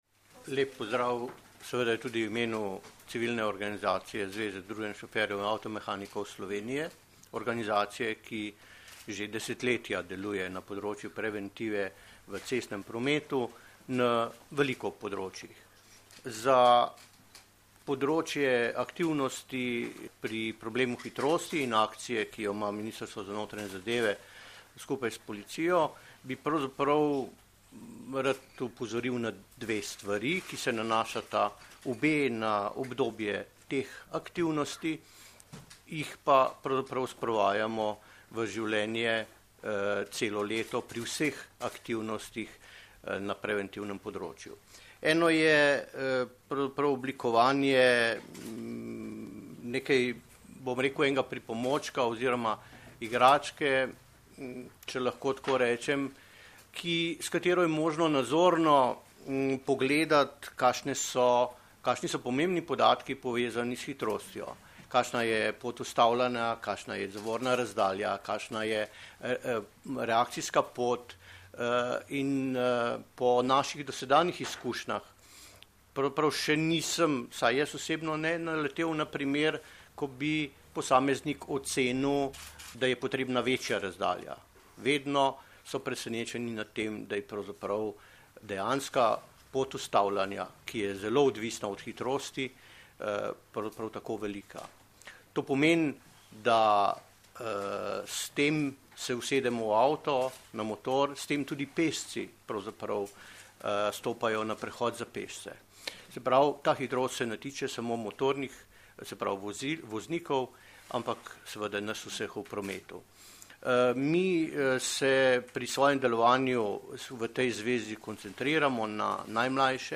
Policija - Policisti začeli s poostrenim nadzorom hitrosti - preventivna kampanja Hvala, ker voziš zmerno - informacija z novinarske konference
Zvočni posnetek izjave